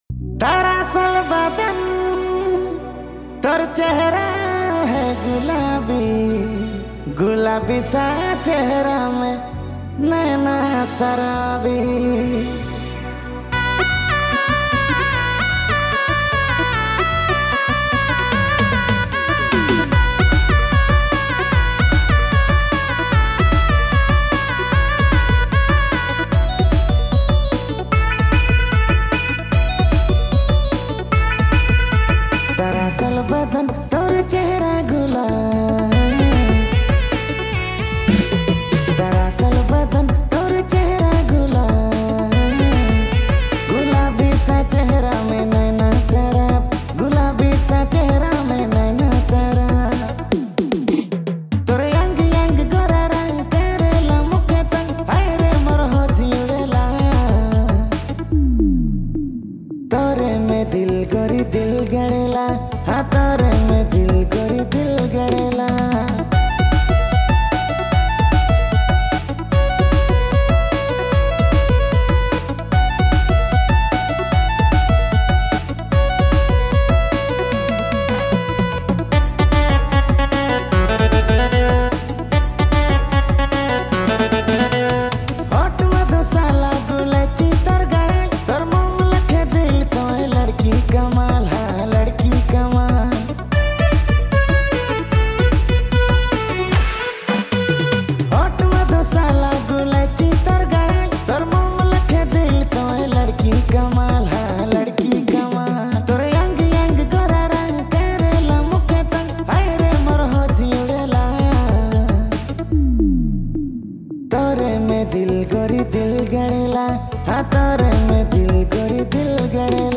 Dj Remixer
February Months Latest Nagpuri Songs